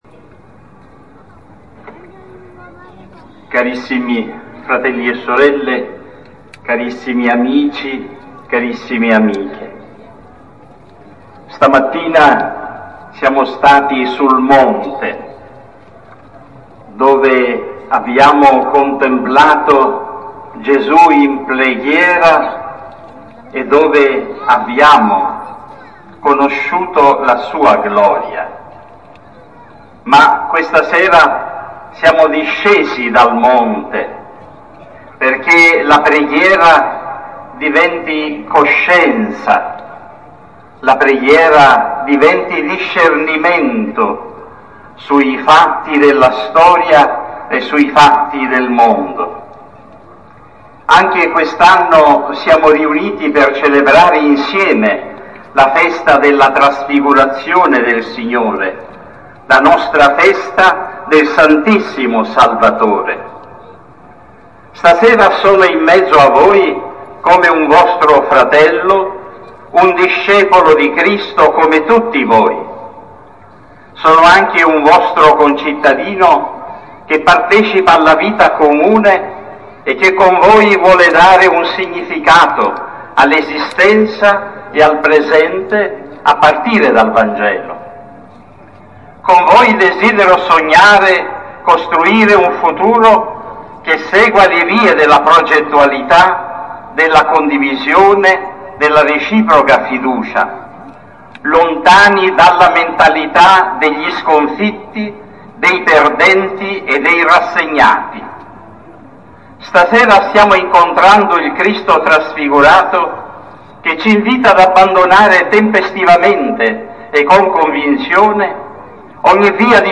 LA PREDICA DEL VESCOVO ALLA FINE DELLA PROCESSIONE: AUDIO